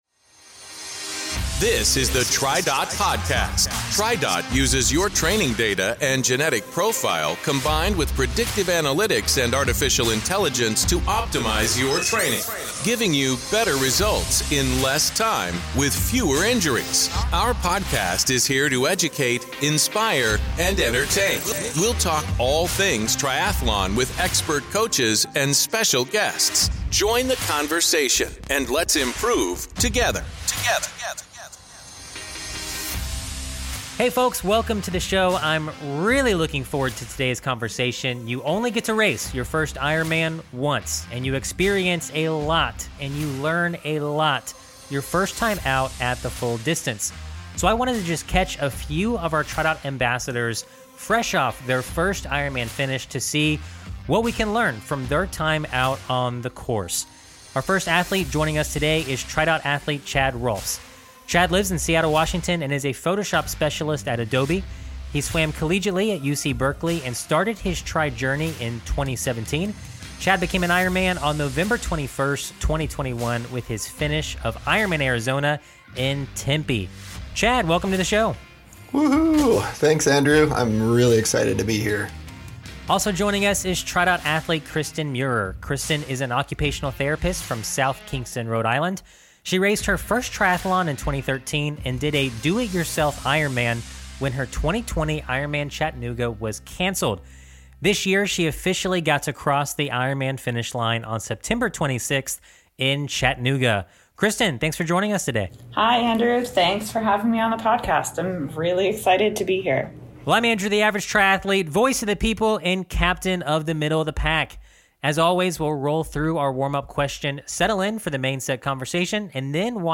Don't miss the reflections from two first-time finishers!